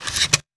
weapon_foley_drop_15.wav